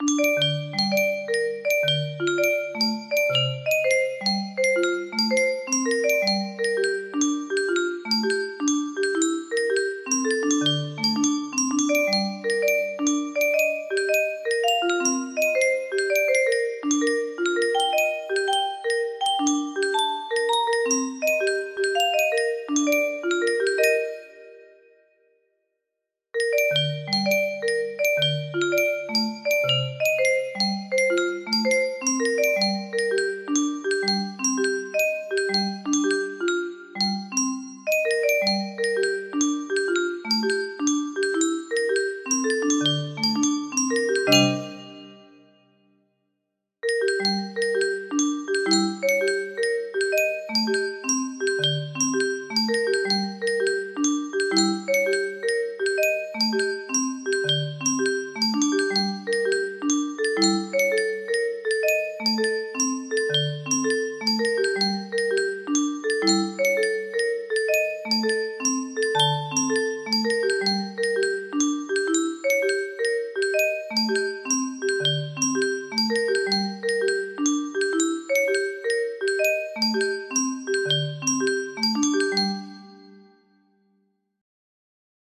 hardly adapted for music box 30 tones